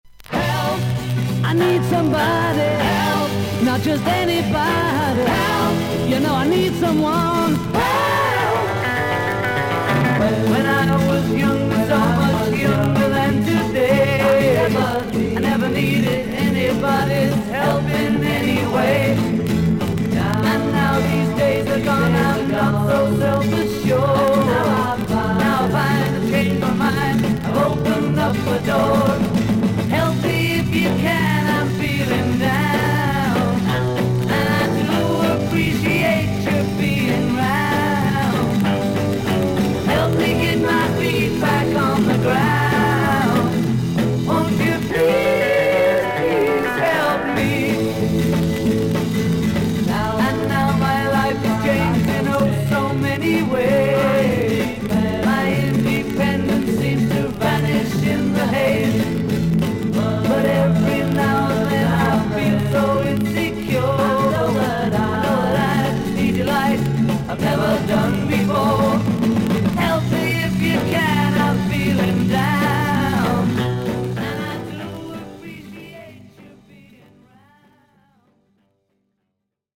音のグレードはA面、VG+:全体的に少々サーフィス・ノイズあり。少々軽いパチノイズの箇所あり。音自体はクリアです。